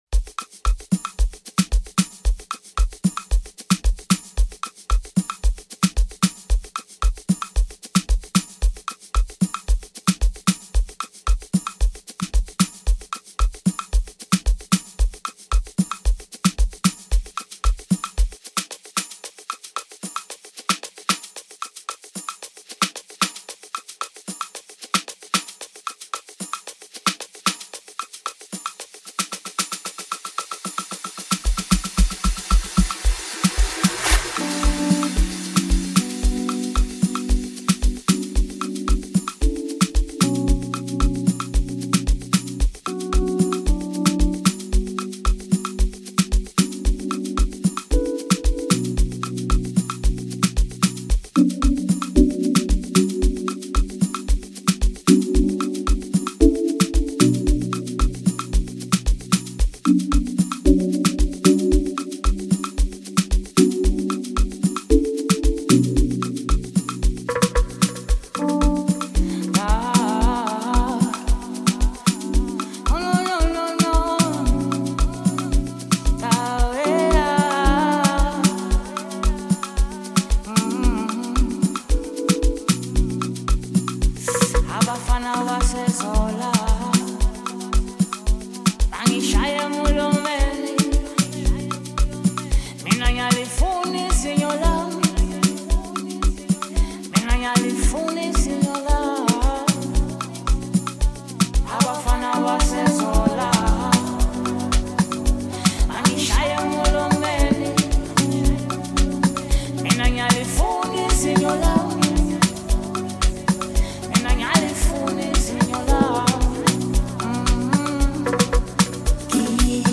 mixtape
solitary Amapiano journey